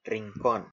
[riŋˈkon] 'corner'
Es-rincón.oga.mp3